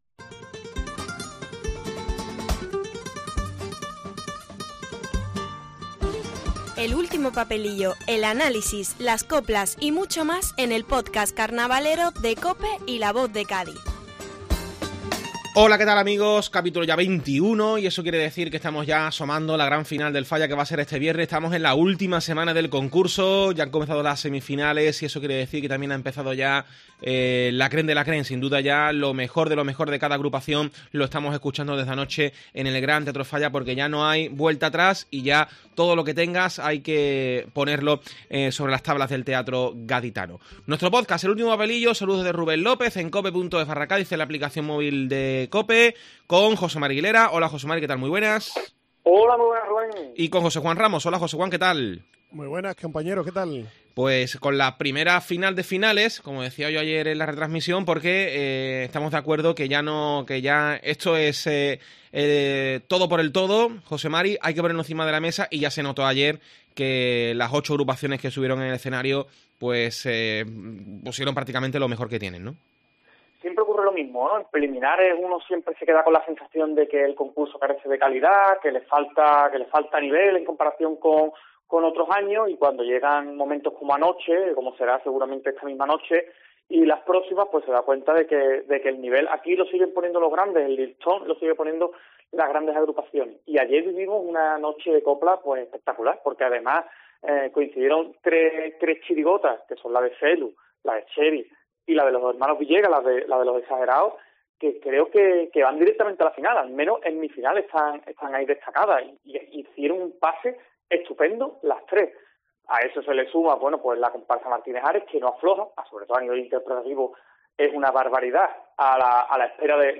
Escucha el podcast de Carnaval con el tiempo de análisis y los sonidos de la primera semifinal del COAC 2024